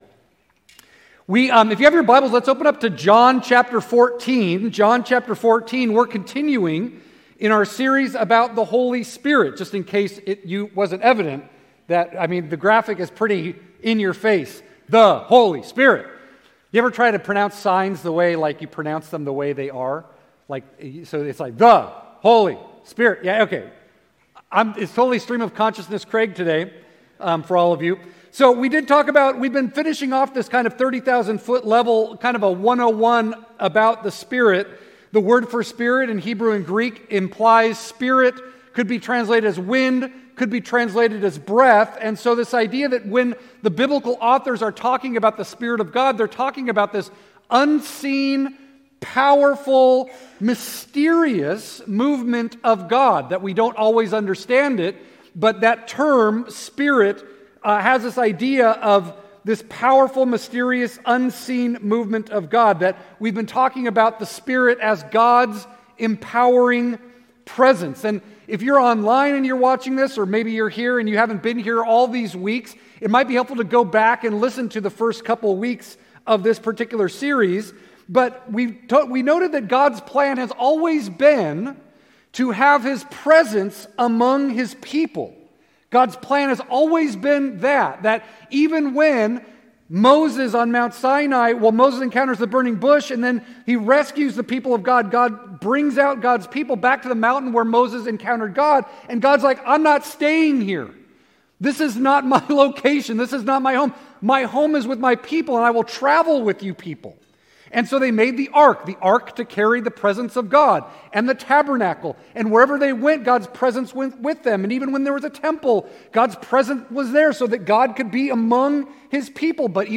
Sermons Podcast - The Advocate | Free Listening on Podbean App